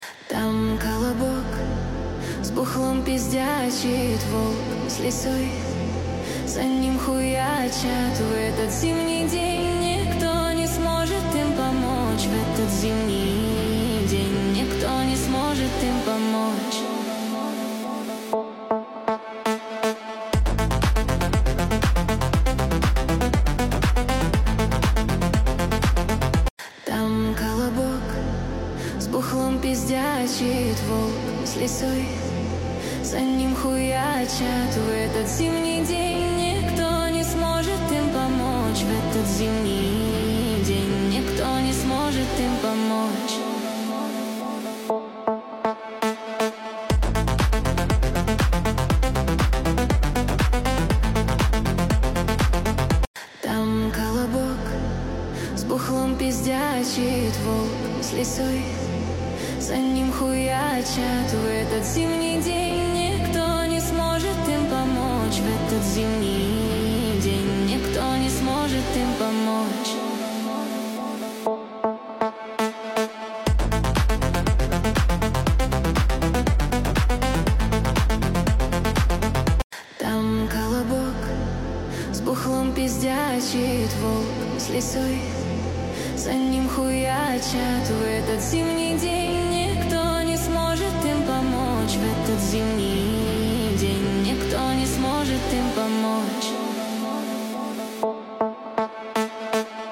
13 декабрь 2025 Русская AI музыка 74 прослушиваний